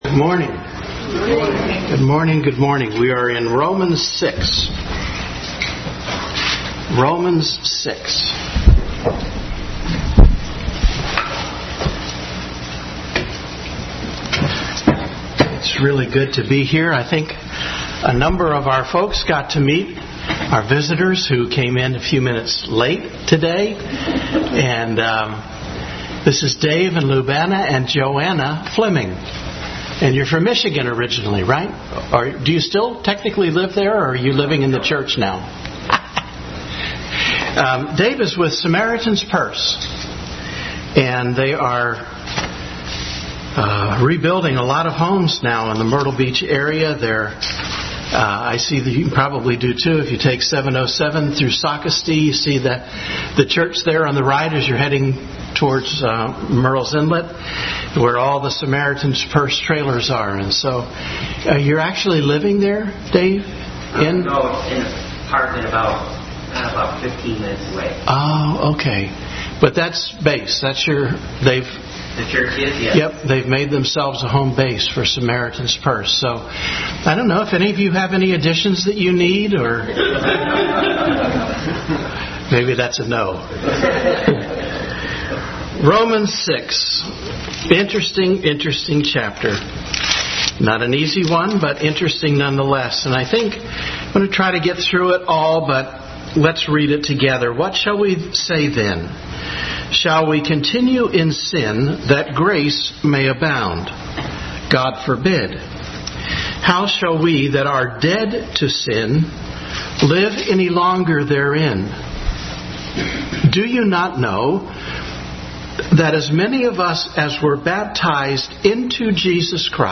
Romans 6:1-23 Service Type: Sunday School Bible Text